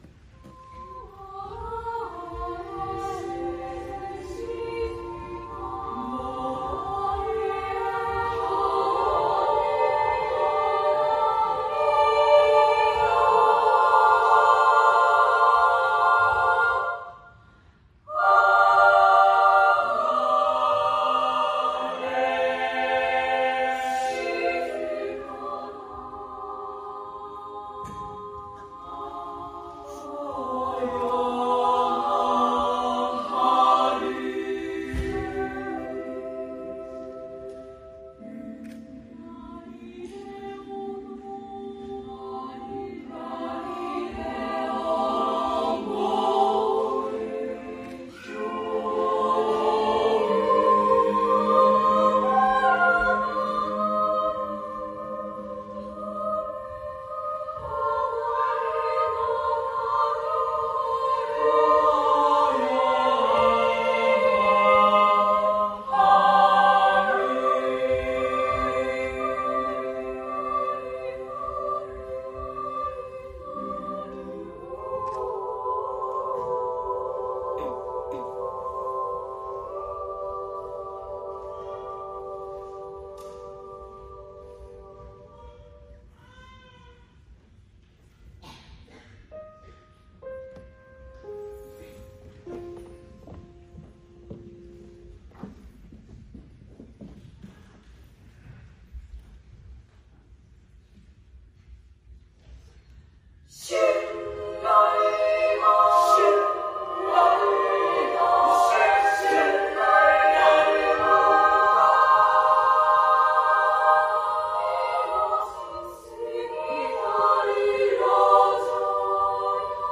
令和元年度 文化祭／合唱部の歌声◇全国大会演奏曲
文化祭での合唱部の歌声を掲載しました。
新鮮な響きを持ったア・カペラ作品。